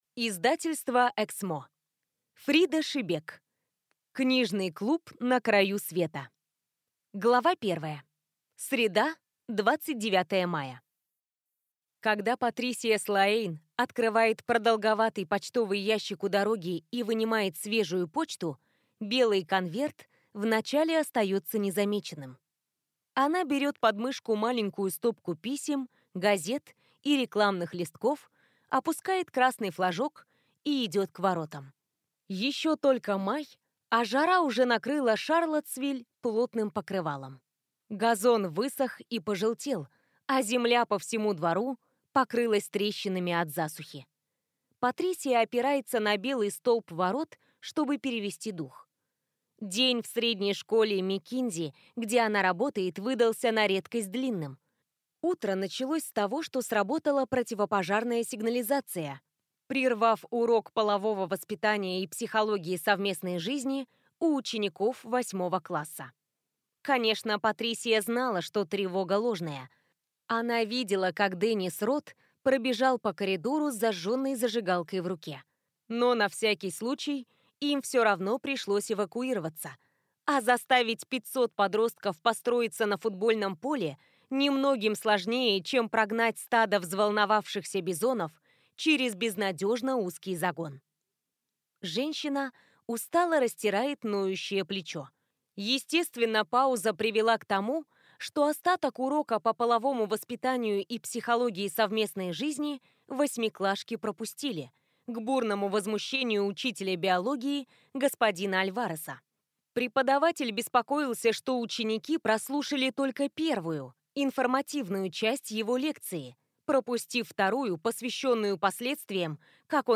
Аудиокнига Книжный клуб на краю света | Библиотека аудиокниг